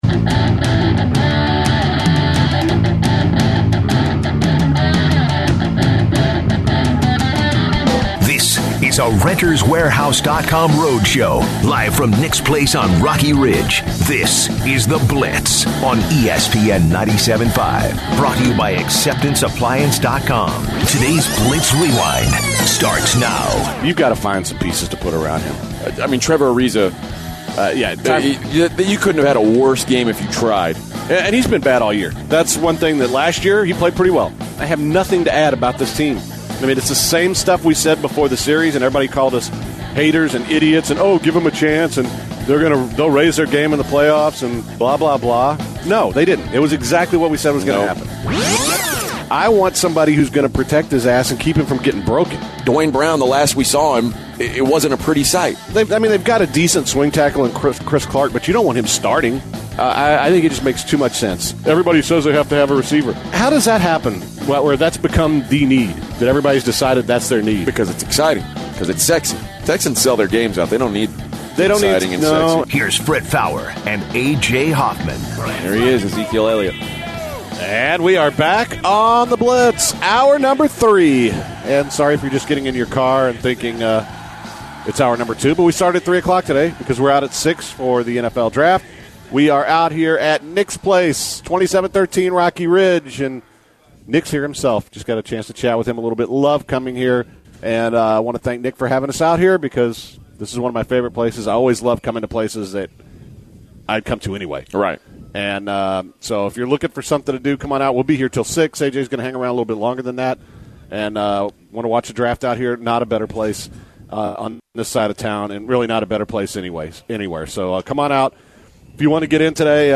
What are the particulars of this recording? continue live for the third and final hour at Nick’s Place.